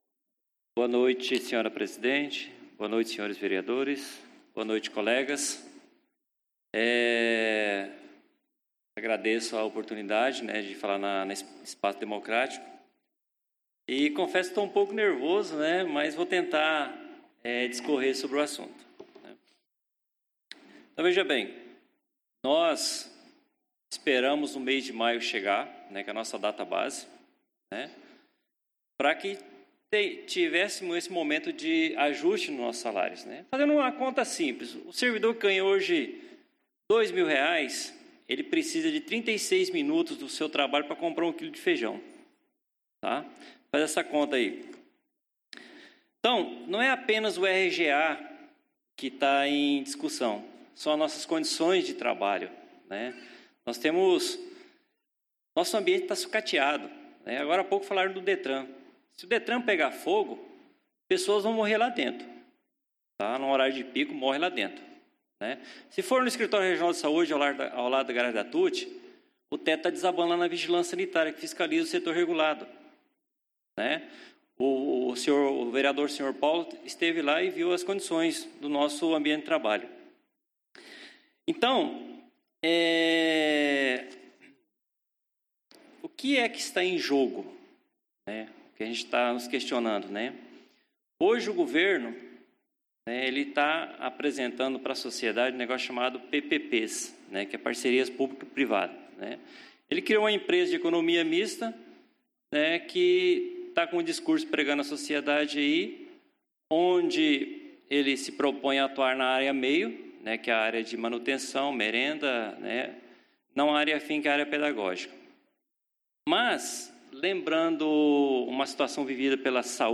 Tribuna do Povo utilizada na sessão ordinária do dia 13/06/2016 as 20 horas no Plenário Henrique Simionatto.